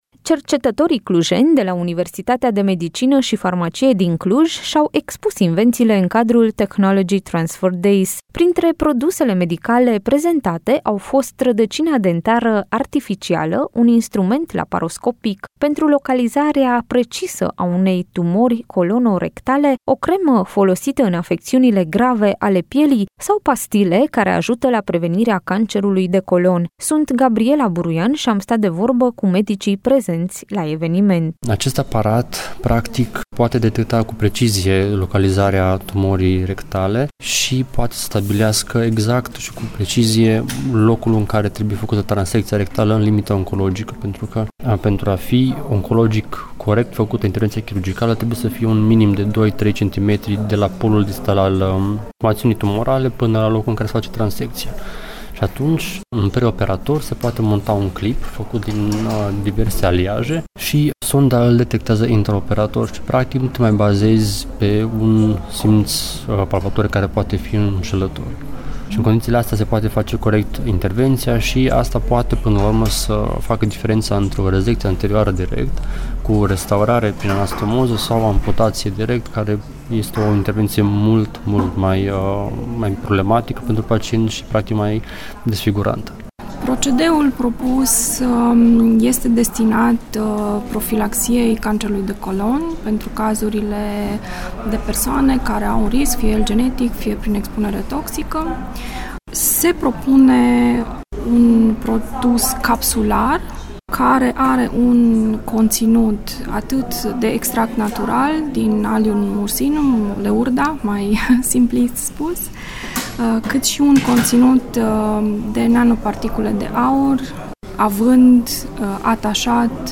Cercetătorii clujeni de la Universitatea de Medicină și Farmacie din Cluj și-au expus invențiile în cadrul Technology Transfer Days. Printre produsele medicale prezentate au fost rădăcina dentară artificială, un instrument laparoscopic pentru localizarea precisă a unei tumori , o cremă folosită în afecțiunele grave ale pielii sau pastile care ar ajuta la prevenirea cancerului de colon.